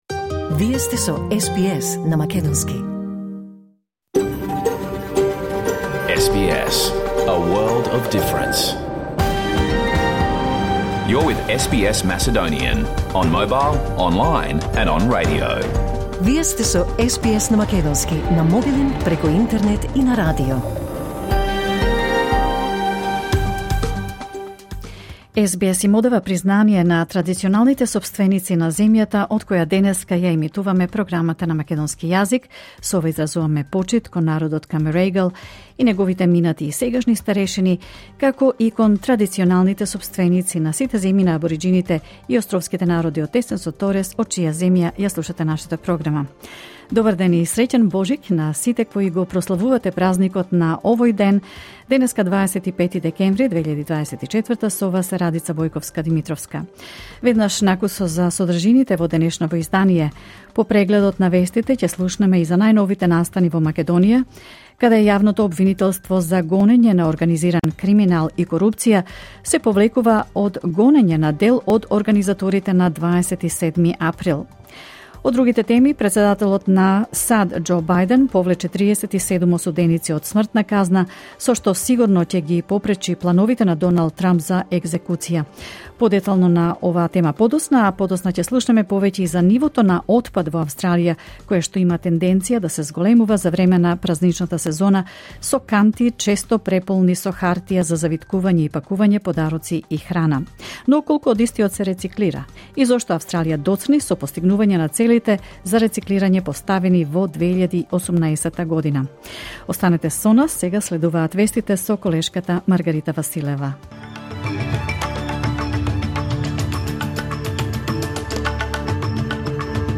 SBS Macedonian Program Live on Air 25 December 2025